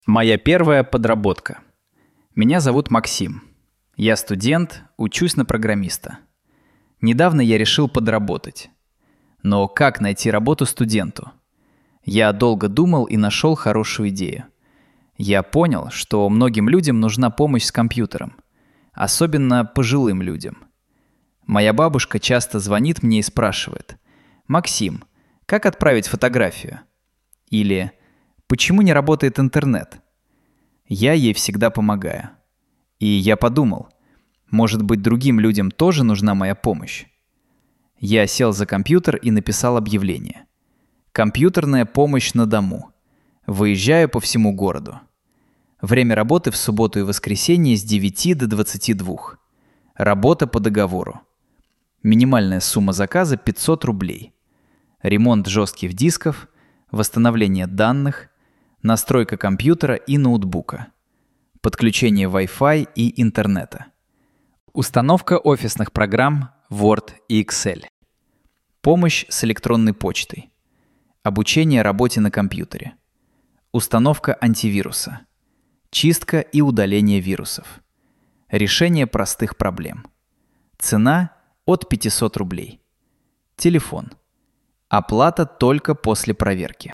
Écoutez une histoire authentique en russe avec traduction française et prononciation claire pour progresser rapidement.